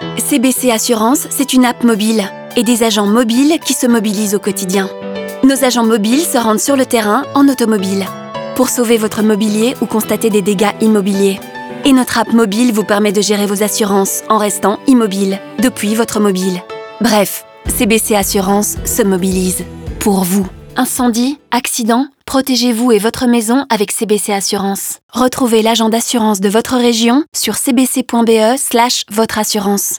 La campagne s’est également vue déclinée en deux spots radio, l’un s’adressant aux particuliers et l’autre aux professionnels.